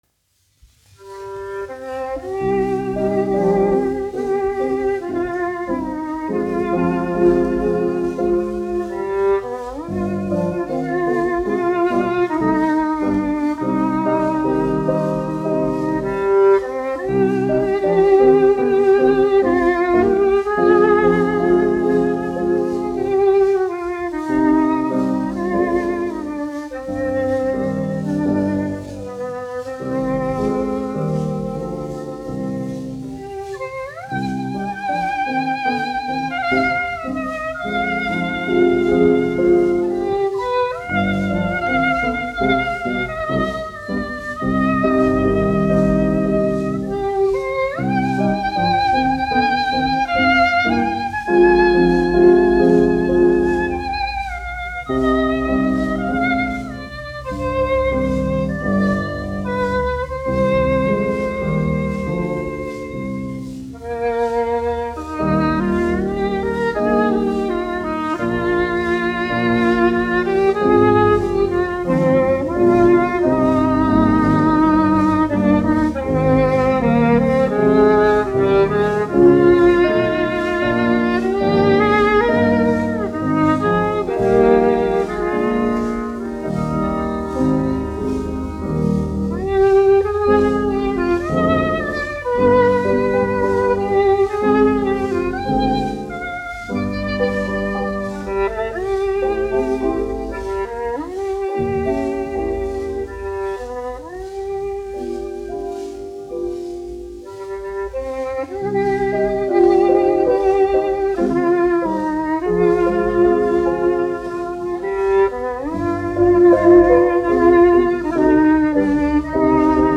1 skpl. : analogs, 78 apgr/min, mono ; 25 cm
Vijoles un klavieru mūzika, aranžējumi
Skaņuplate